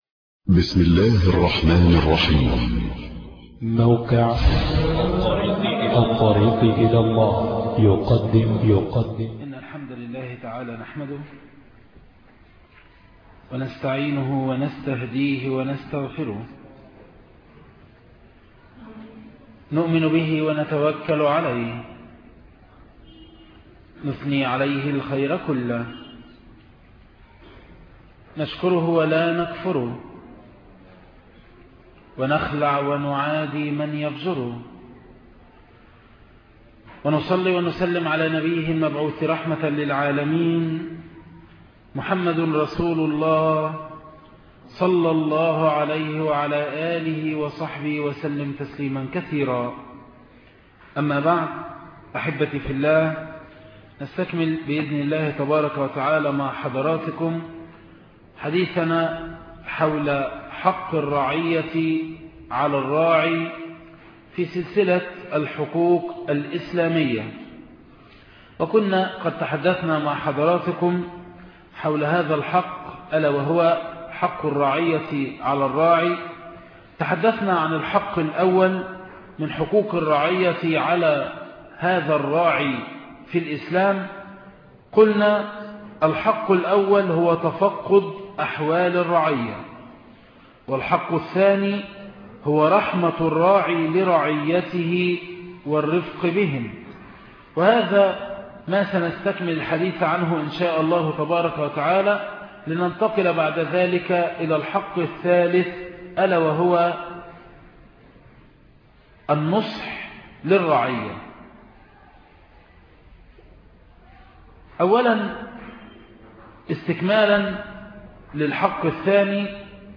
حق الرعيه على الراعى الدرس الثالث